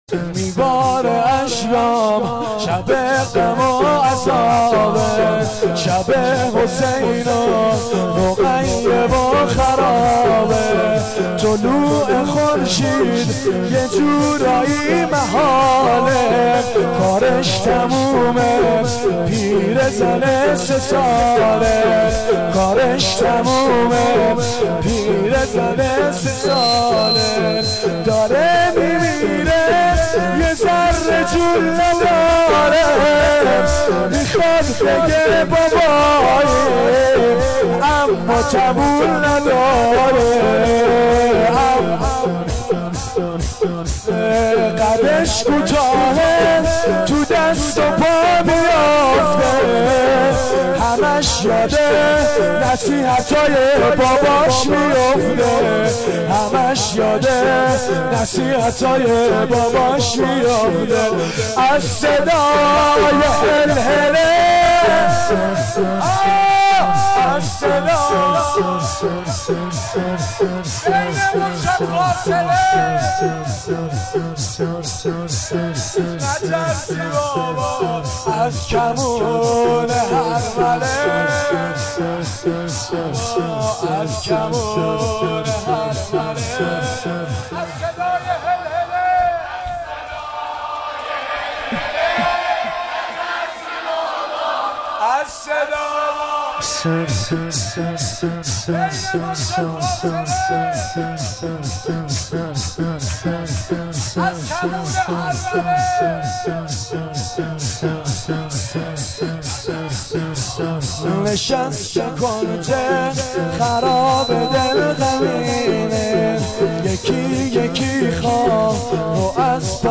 شور
شب سوم محرم ۱۴۴۱